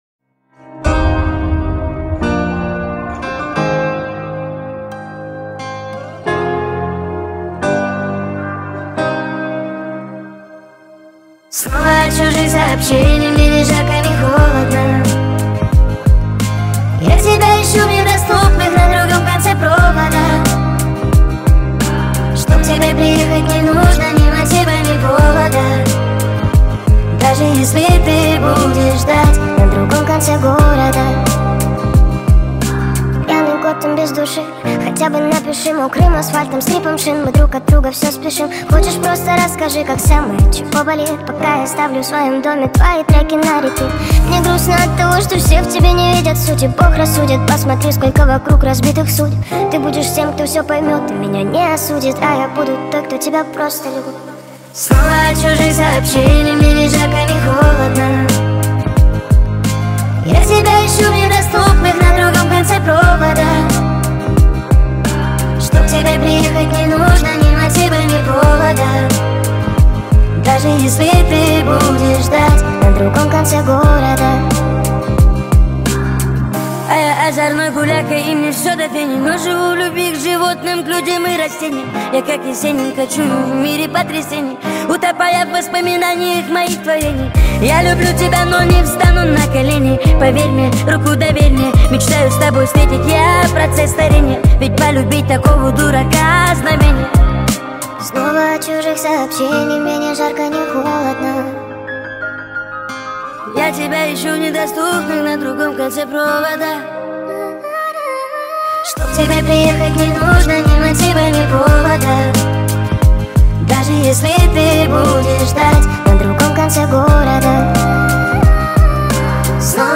sped up tik tok remix